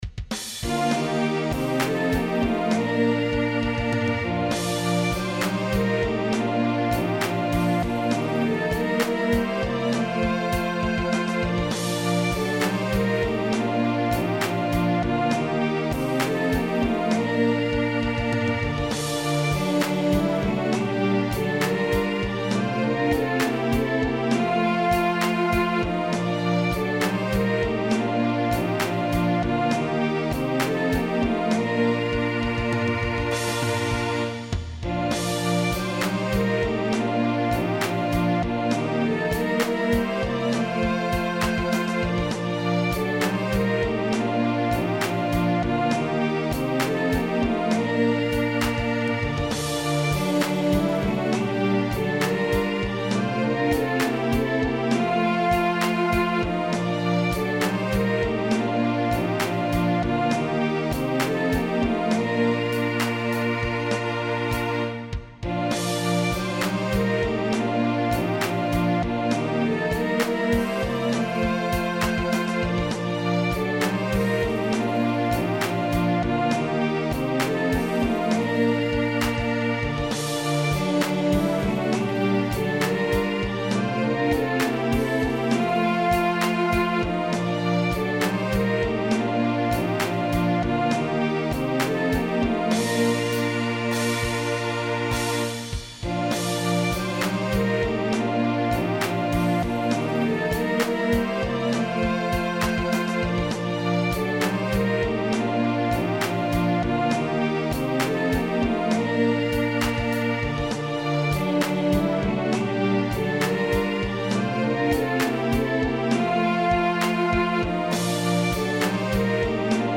An old favorite Christmas song.
Recording from MIDI